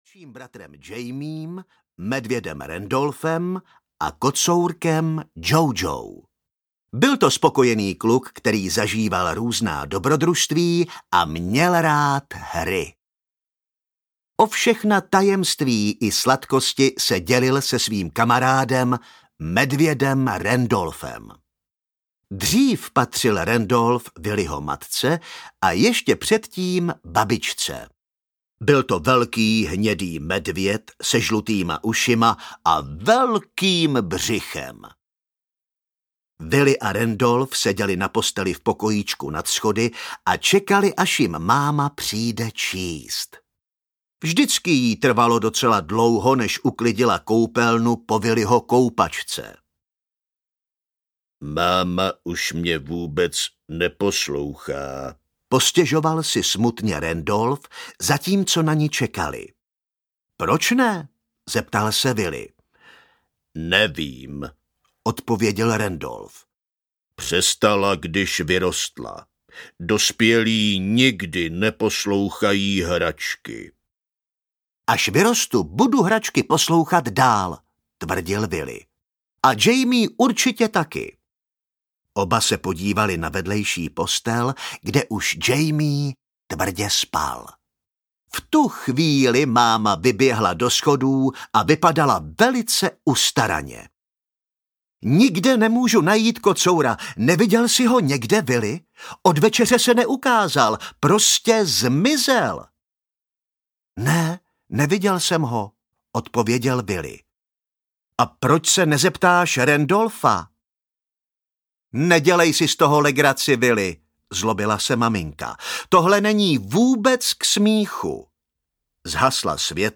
Willy v Hranatém světě audiokniha
Ukázka z knihy